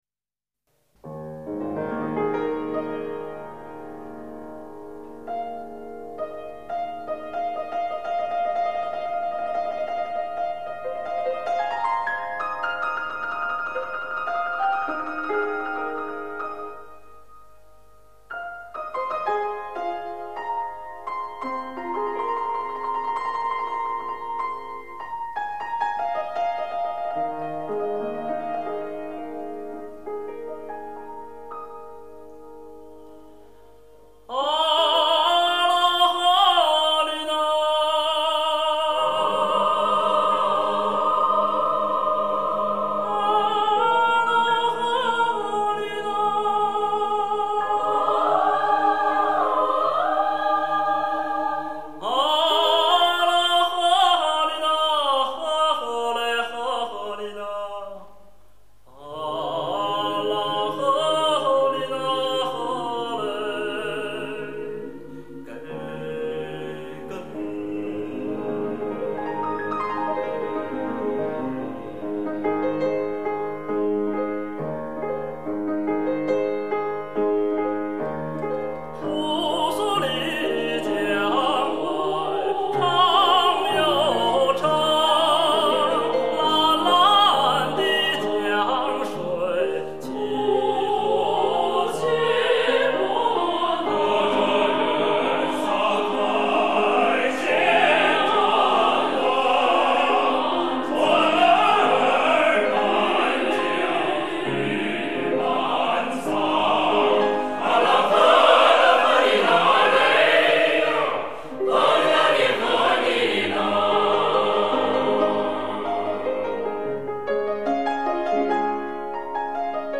音乐类型：民乐